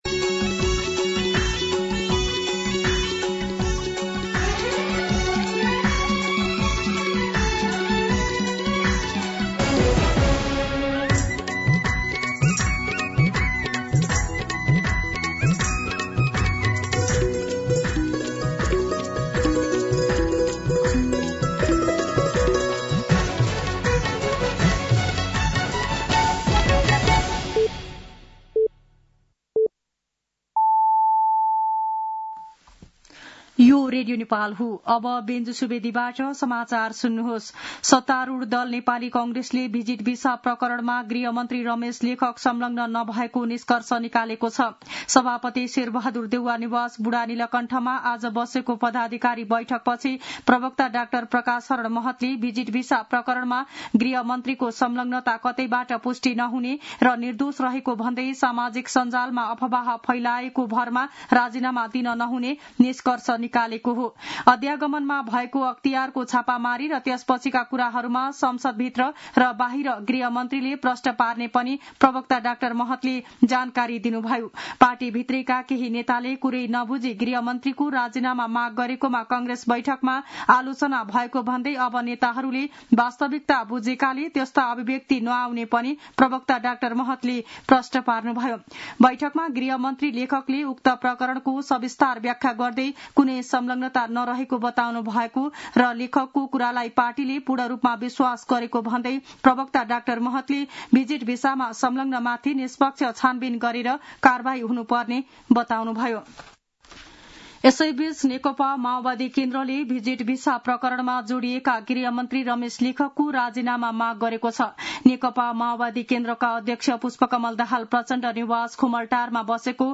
मध्यान्ह १२ बजेको नेपाली समाचार : १२ जेठ , २०८२